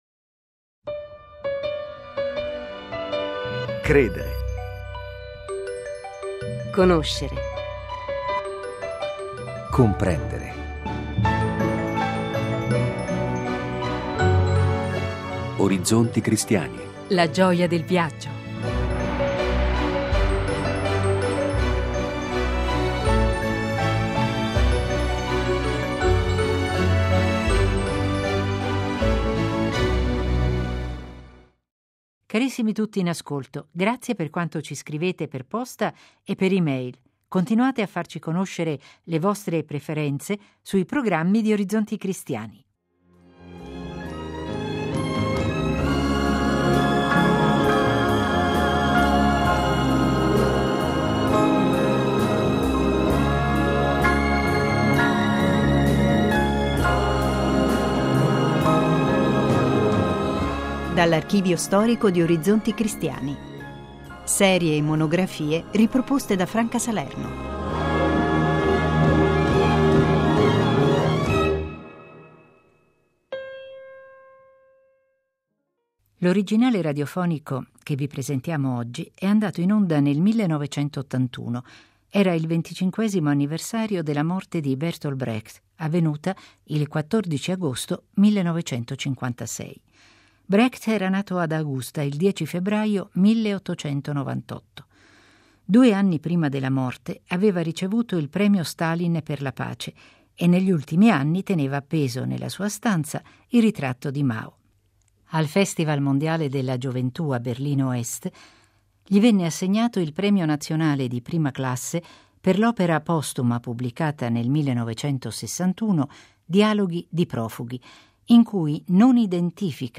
sabato 25 agosto L’originale radiofonico che vi presentiamo oggi è andato in onda nel 1981 ricorrendo il 25mo anniversario della morte di Bertolt Brecht, avvenuta il 14 agosto 1956.